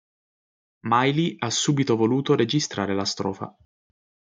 strò‧fa (IPA) olarak telaffuz edilir /ˈstrɔ.fa/